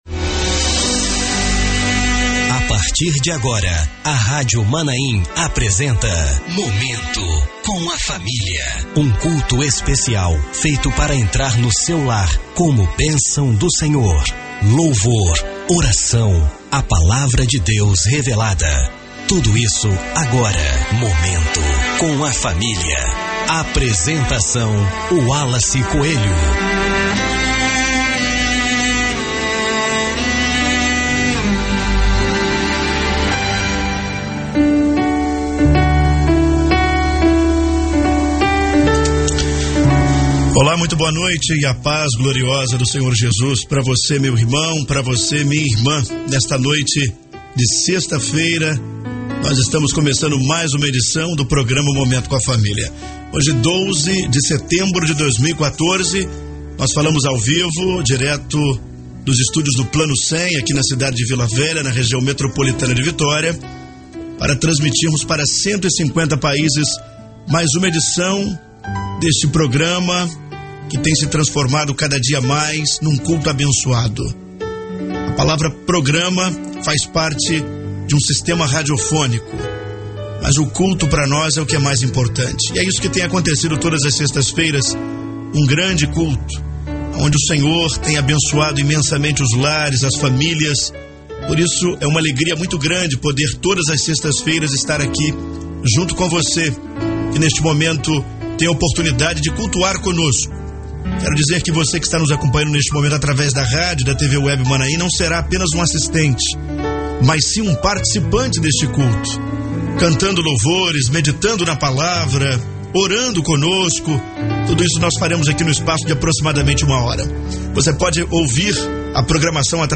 Culto com transmissão via satélite - Rádio Maanaim